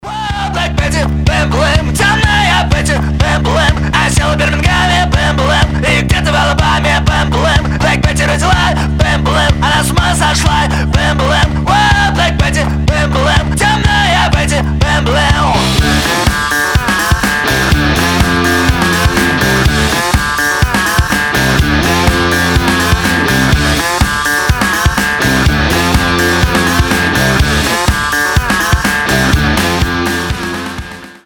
• Качество: 320, Stereo
громкие
Драйвовые
Cover
Alternative Rock
Hard rock
банджо